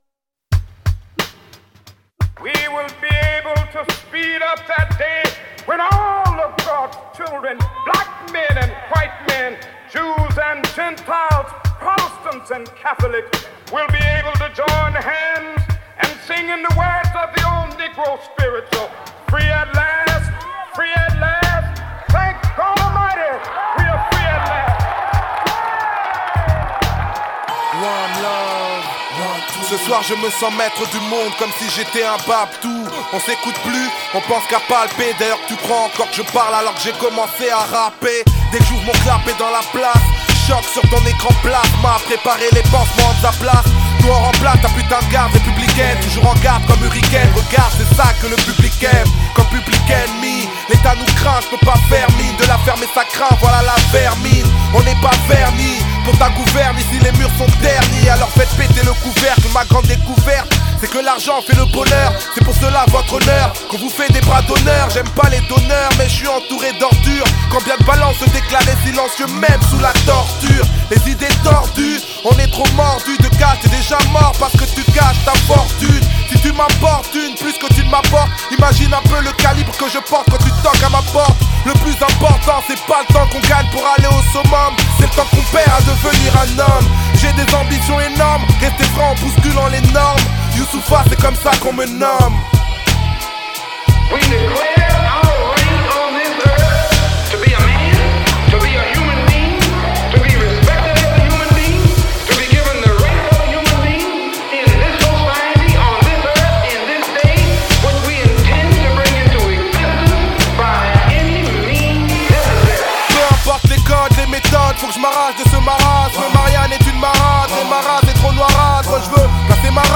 Genre: French Rap